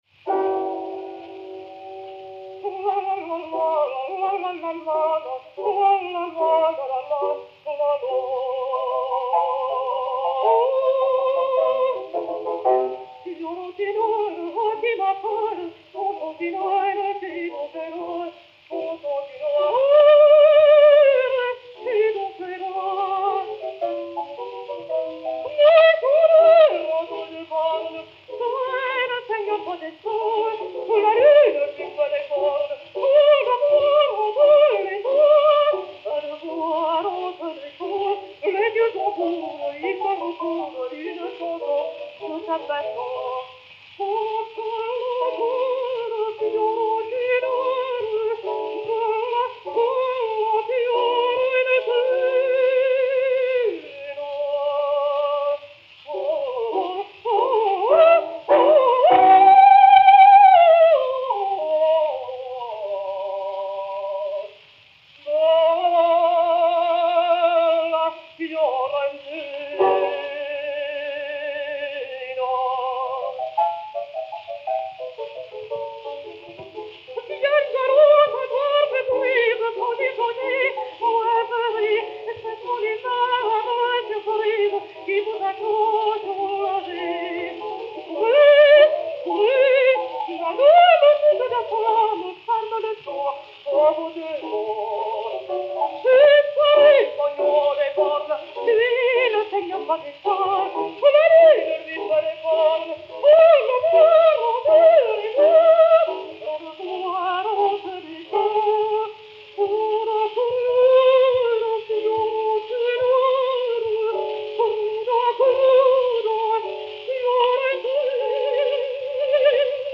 et Camille Saint-Saëns au piano
Disque Pour Gramophone 33470, mat. 3459 G, enr. à Paris le 26 juin 1904